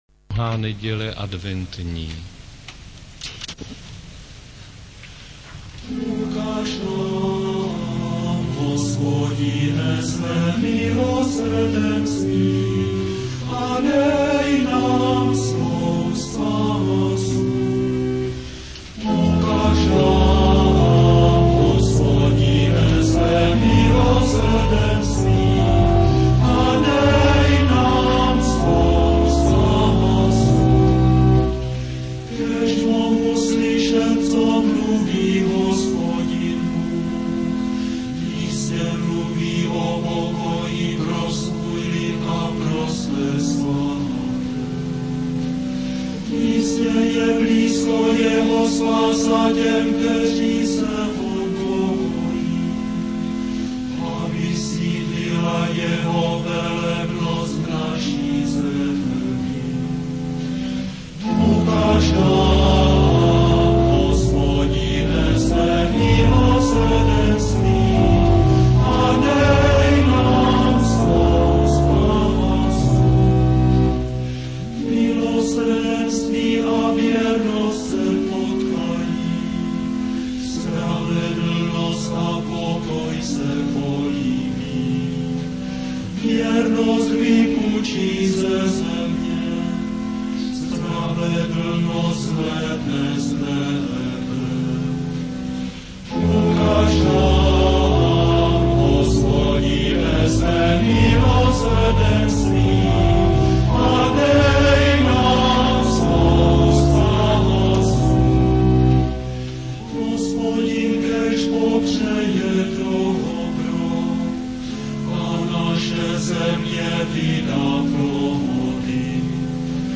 křesťanská hudba, liturgická hudba